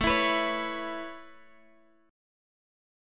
Listen to Aadd9 strummed